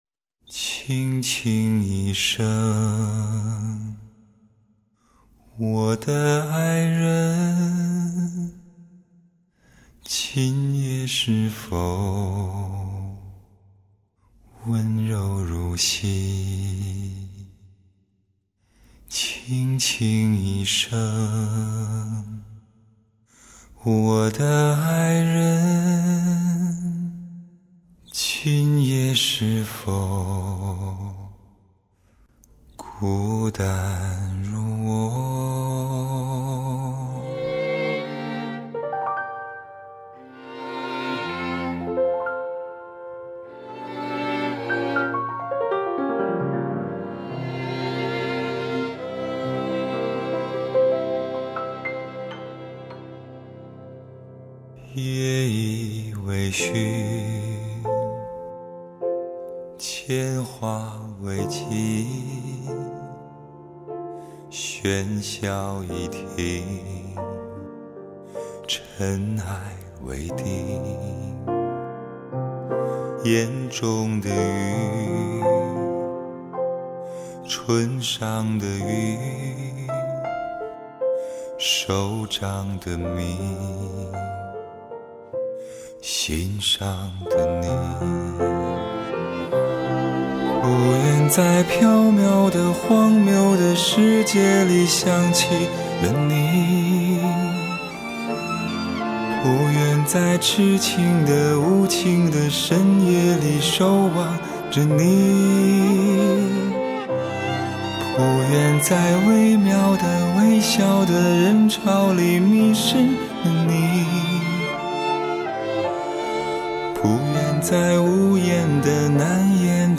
男低音